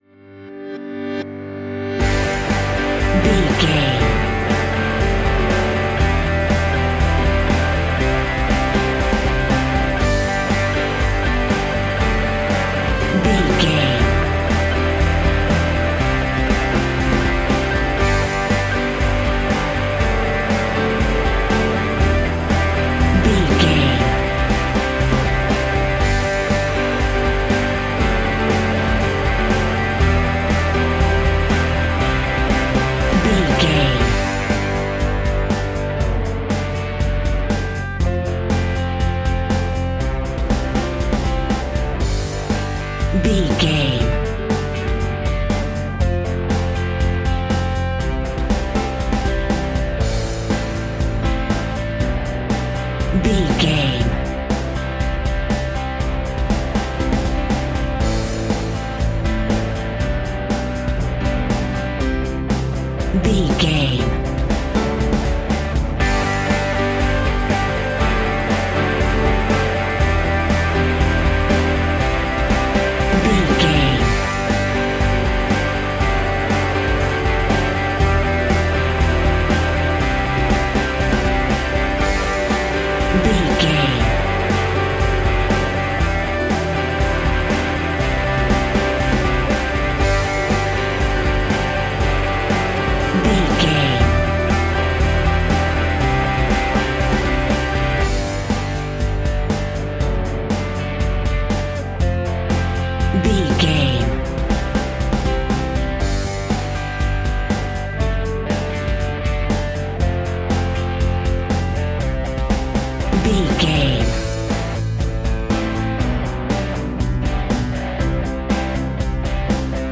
High School Pop Rock.
Fast paced
Ionian/Major
indie rock
sunshine pop music
drums
bass guitar
electric guitar
piano
hammond organ